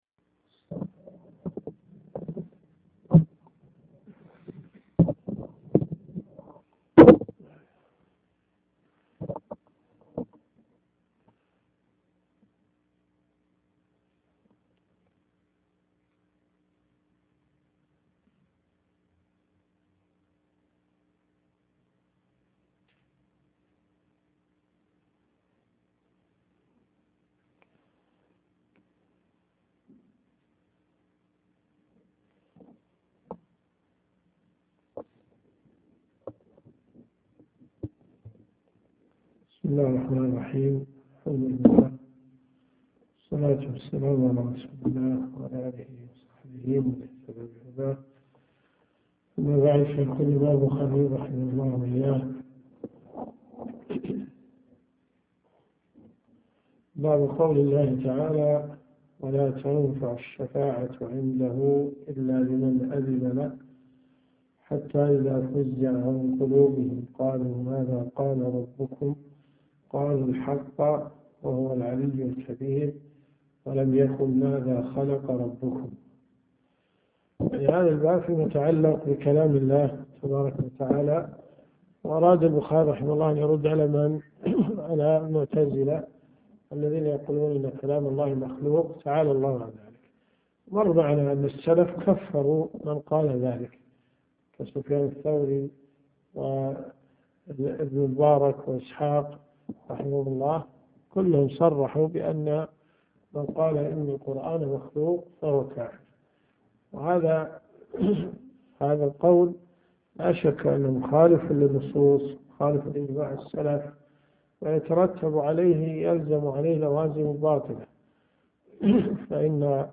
دروس صوتيه